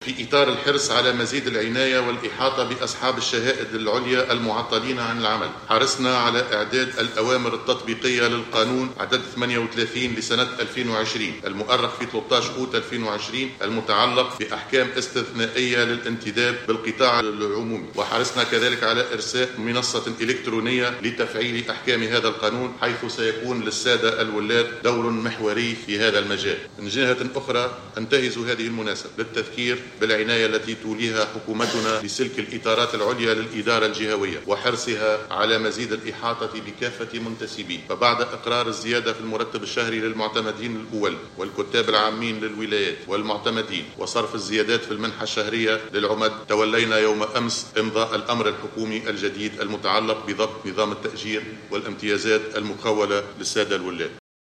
أكد رئيس الحكومة، هشام المشيشي خلال ندوة الولاة المنعقدة اليوم بثكنة الحرس الوطني بالعوينة، أنه أمضى يوم أمس أمرا حكوميا يتعلق بضبط نظام التأجير والامتيازات للولاة.